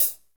HAT A C CH0F.wav